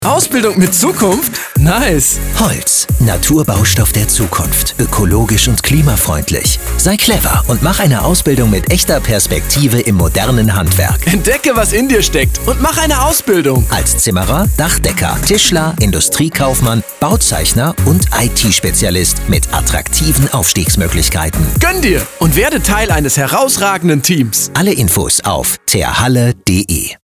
Radiospot „Ausbildung bei Terhalle“
Radiospot-Ausbildung-bei-Terhalle.mp3